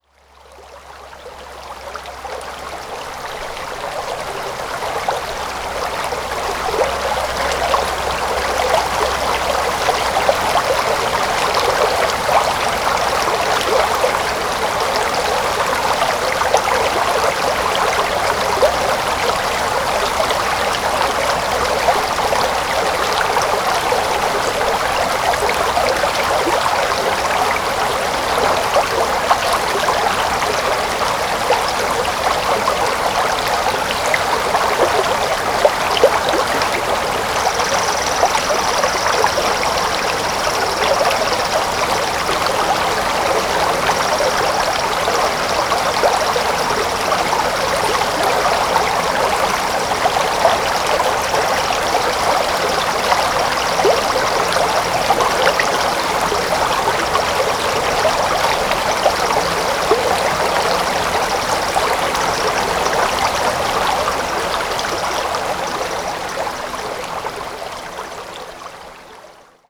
Recordings from the trail through the peaceful second growth forest at Hemer Provincial Park in early spring 2022.
3. Creek – still working on finding whether it has a name or not. There’s also a creature making an alarm-like sound. Squirrel? Bird?